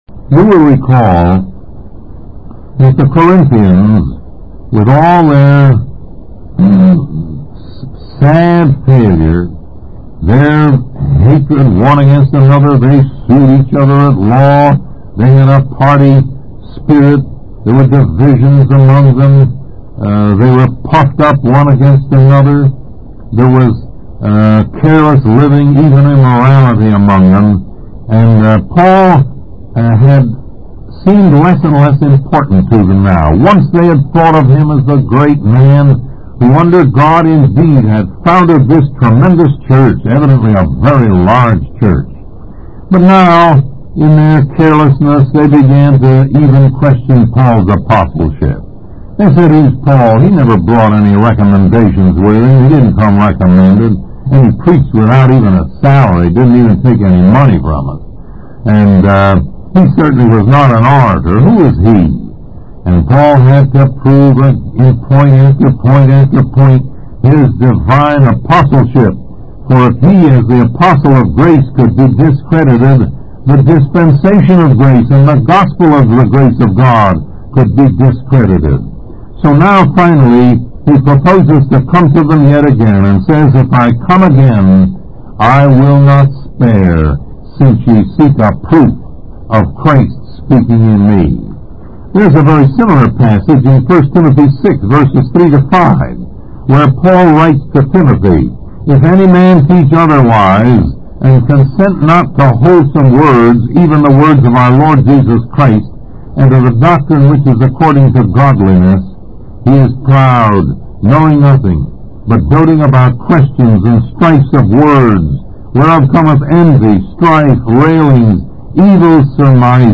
Lesson 45: The Words of Christ